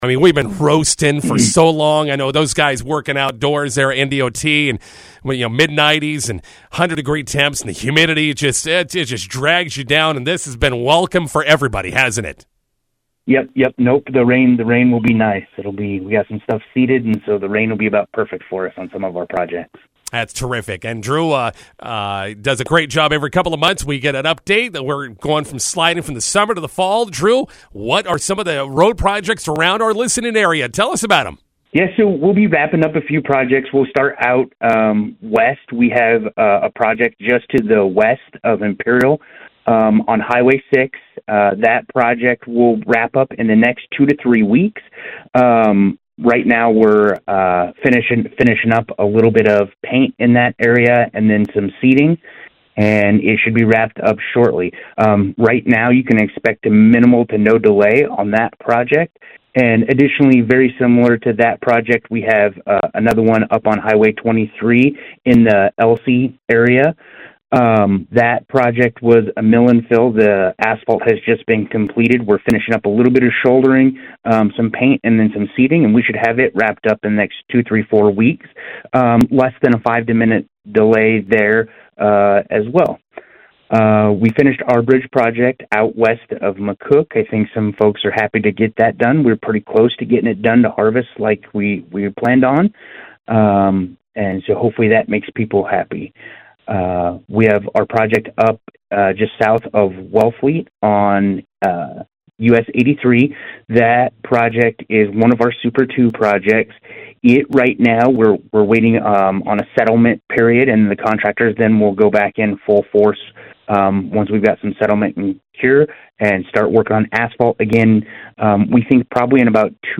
INTERVIEW: Nebraska Department of Transporation preparing for fall road projects.